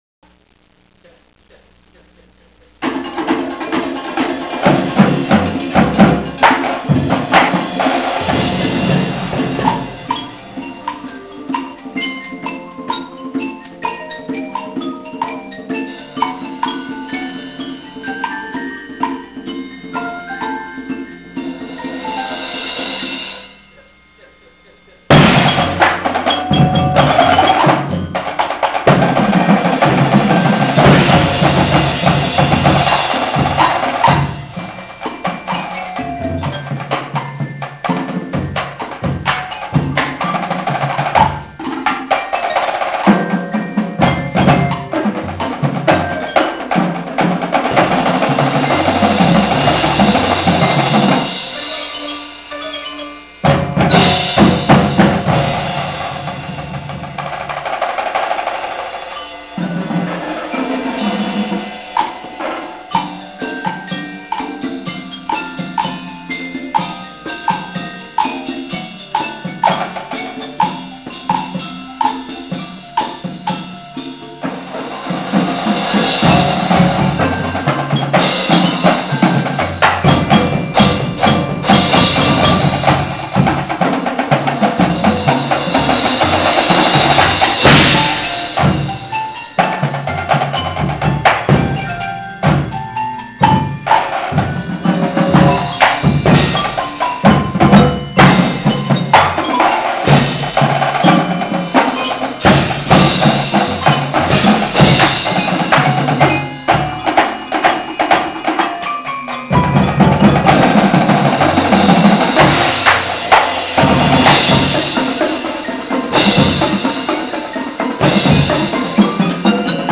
Drumline
Sounds from the 1998 AHS Drumline: